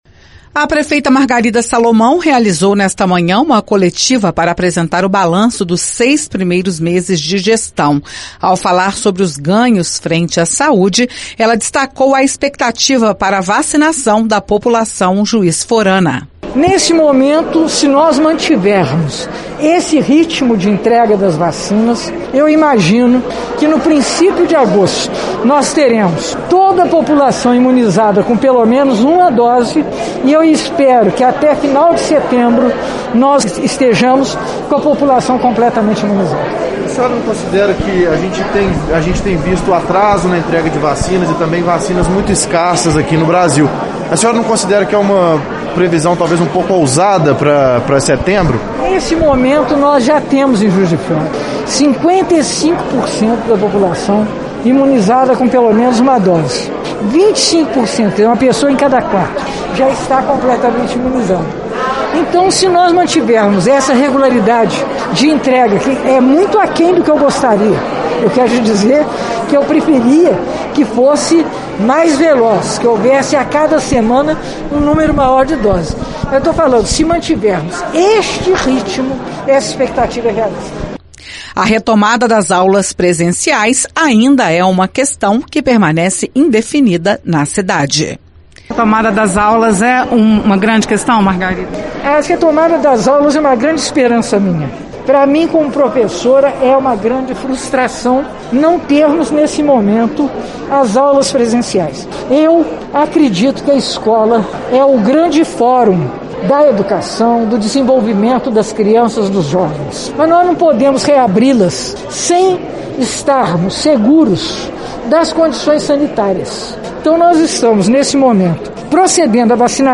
Nesta quinta-feira, 1º, a prefeita de Juiz de Fora, Margarida Salomão realizou coletiva de imprensa para apresentar balanço dos seis primeiros meses de gestão frente à prefeitura.